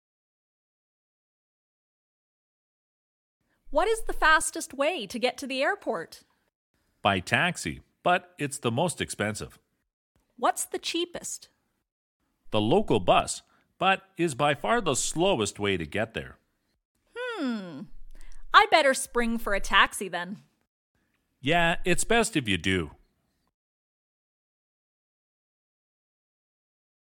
Woman: What is the fastest way to get to the airport?
Man: By taxi, but it’s the most expensive.